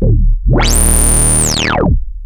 OSCAR  9 D#1.wav